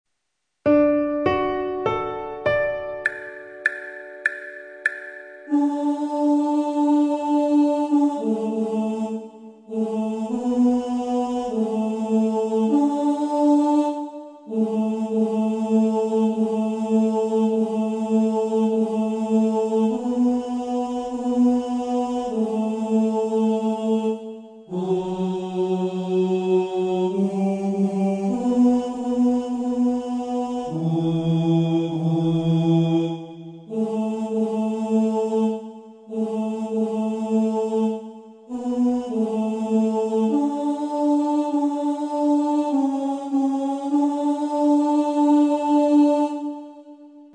If you would like to practice up on a vocal part, here are some part recordings which may assist you.